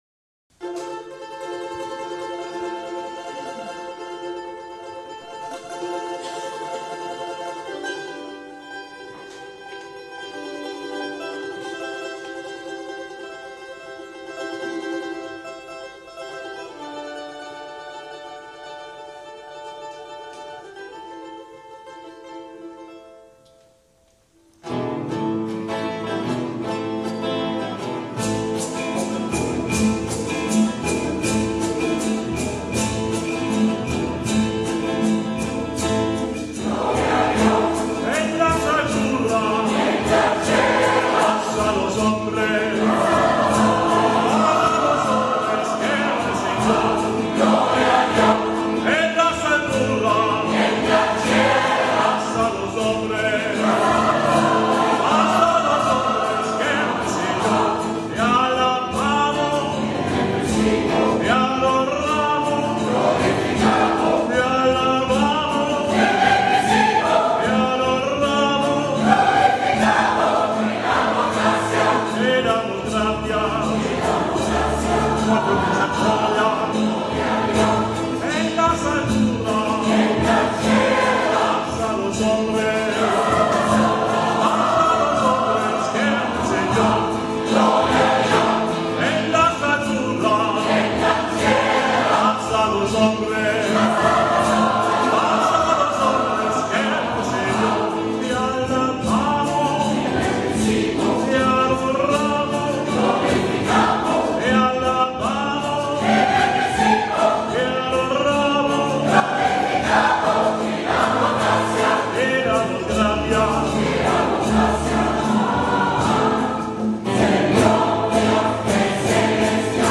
Jiddische Lieder, Spriruals, Misa Criolla